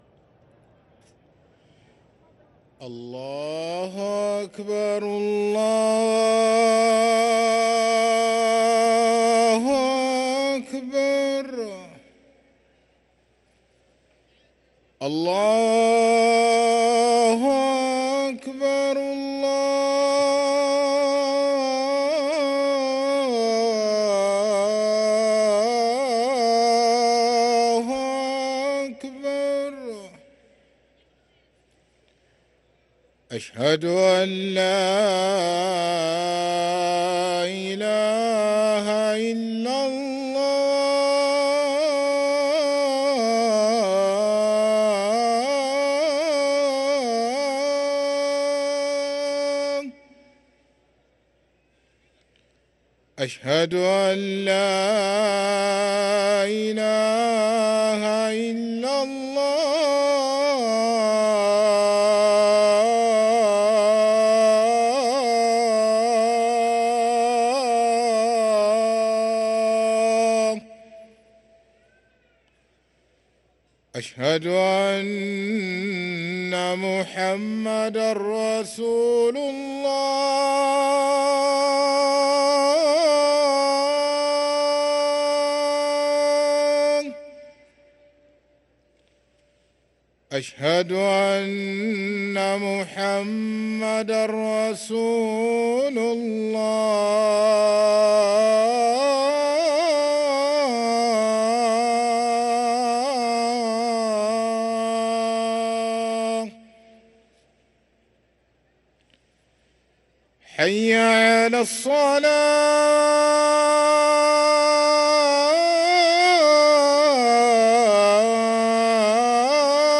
أذان العصر للمؤذن سعيد فلاته السبت 12 شعبان 1444هـ > ١٤٤٤ 🕋 > ركن الأذان 🕋 > المزيد - تلاوات الحرمين